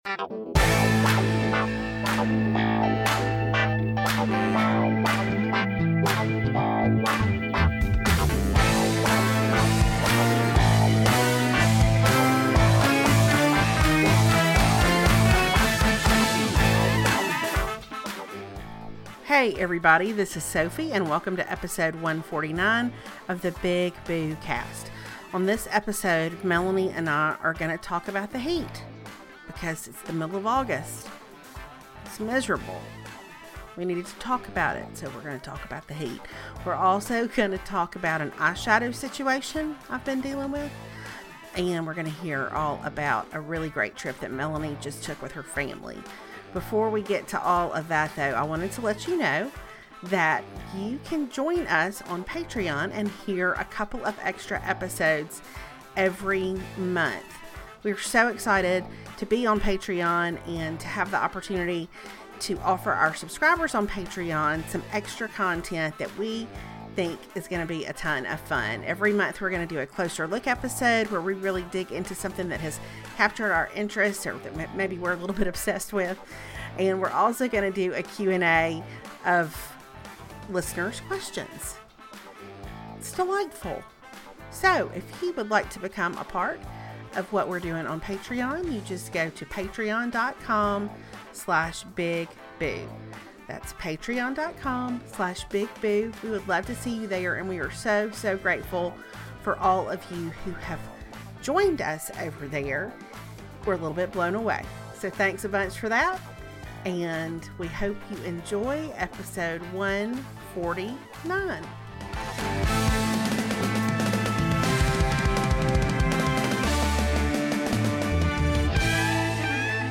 Well, you'll never believe this, but something is weird with my sound on this episode.